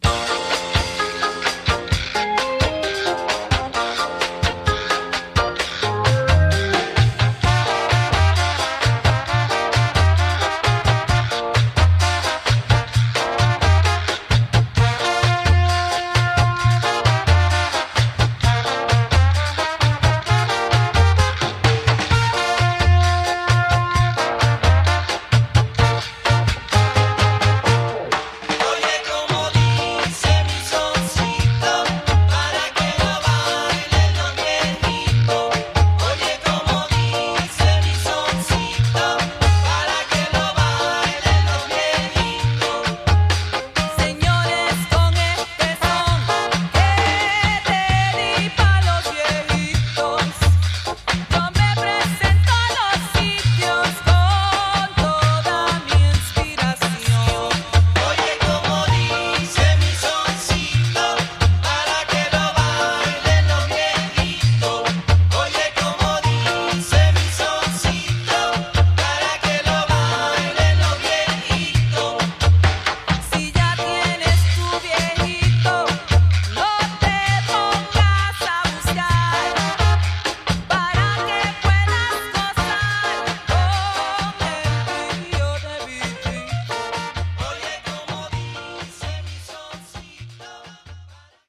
Tags: Reggae , Salsa